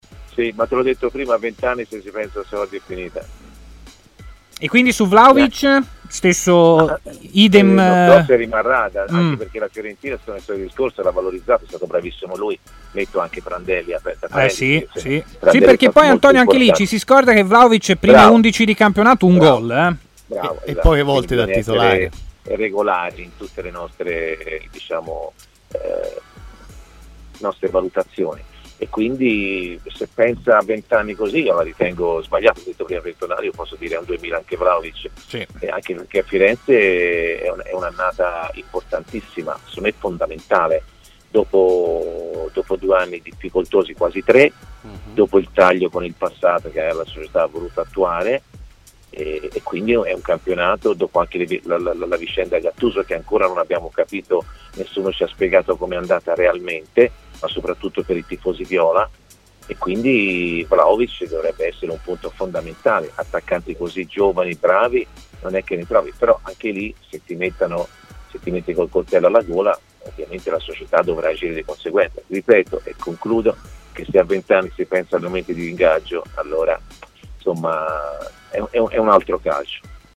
L'ex centrocampista Antonio Di Gennaro, opinionista di TMW Radio, ha così parlato nella trasmissione Stadio Aperto.